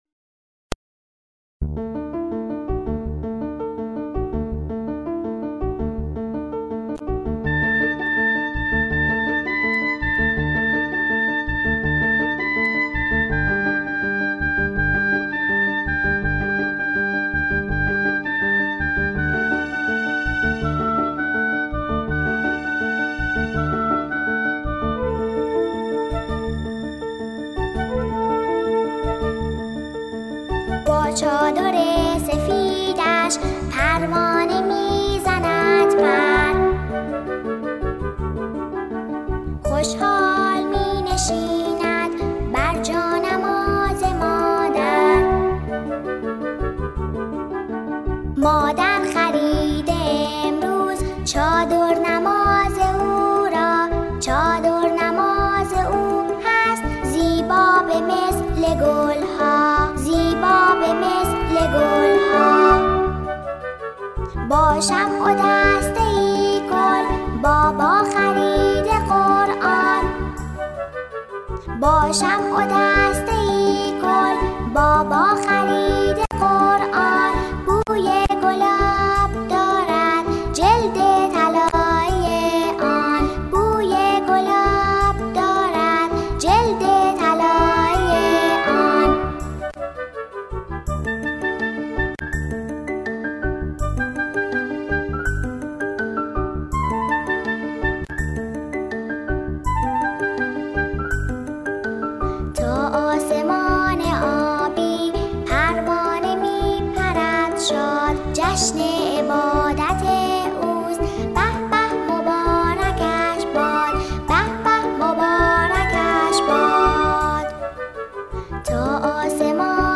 همخوانی
با صدای تکخوان کودک